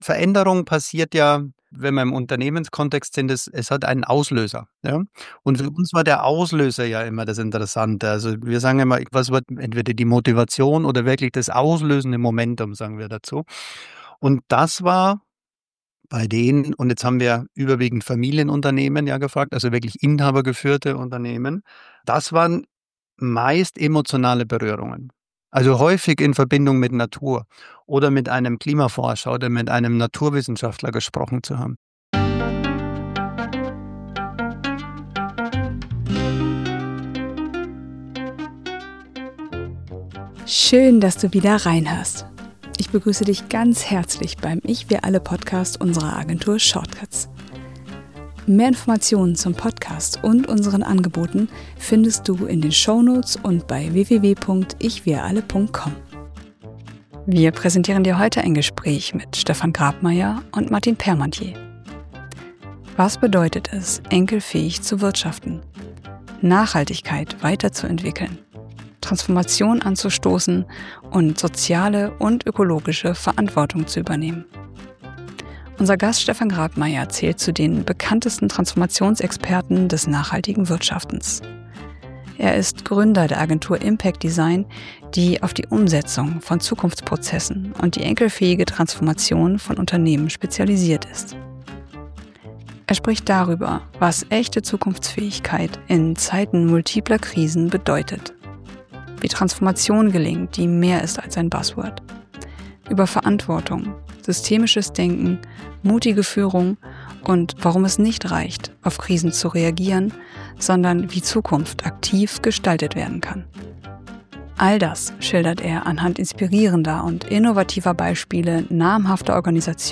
Ein Gespräch über Haltung, Wirkung und radikale Klarheit.